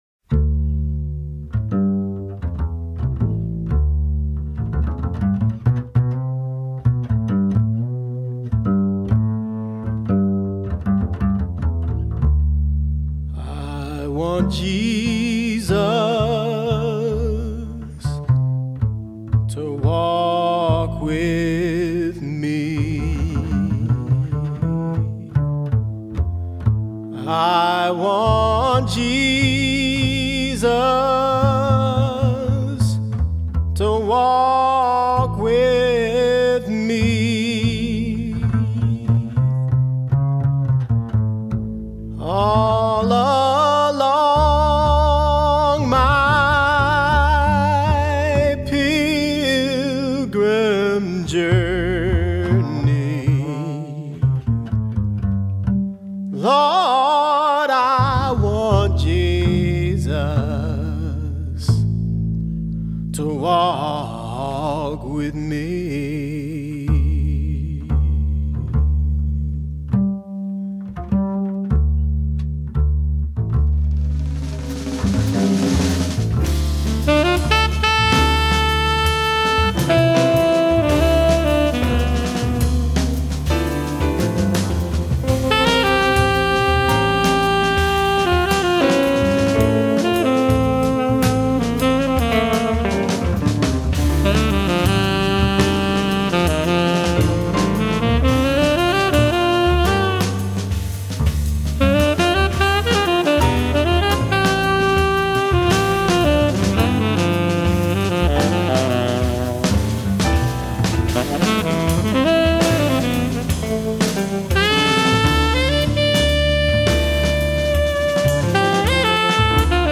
Features original songs, one standard and a Spiritual.
vocals and sax
piano
guitars
bass
drums